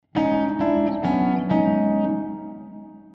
Chamber Reverbs
Similar to hall reverbs, only more well behaved. Tighter and less delay build up. A cleaner sound.
Basic Chamber Reverb
hfx6_6_Basic_Chamber_Reverb.mp3